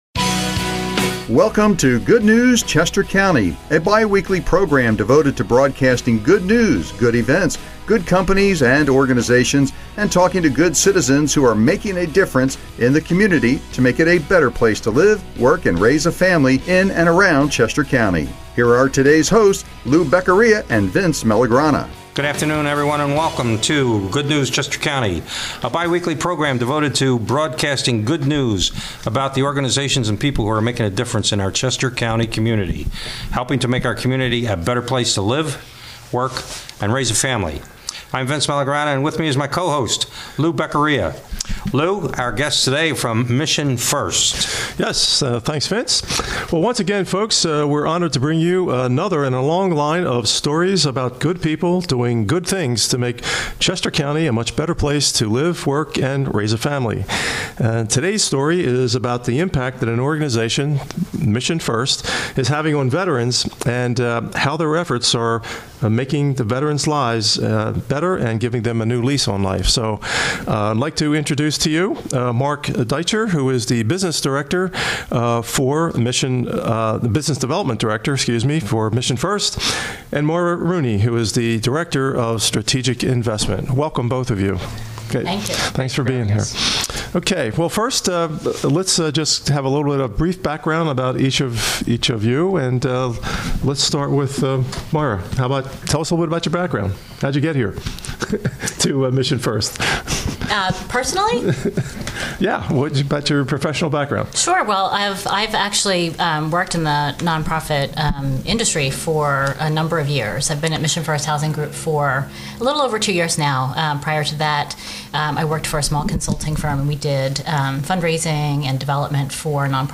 Interview with “Good News Chester County”